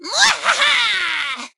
nita_start_vo_02.ogg